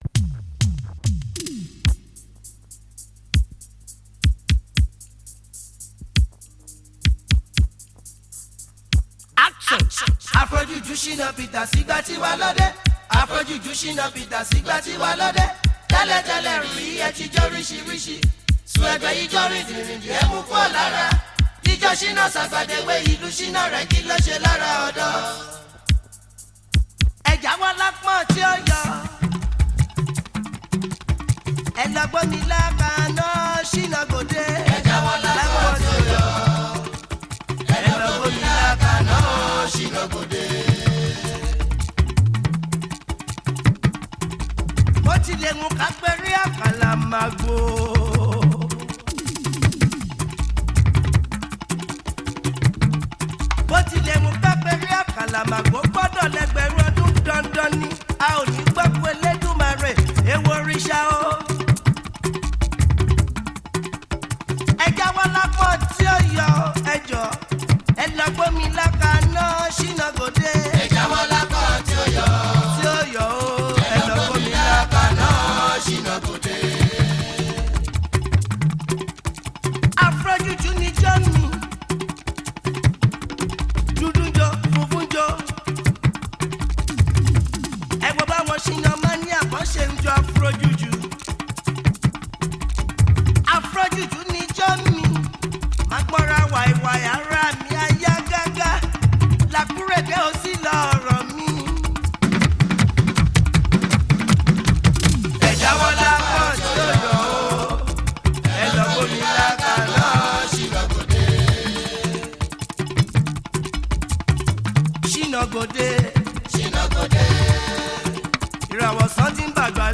is a Nigerian Jùjú musician.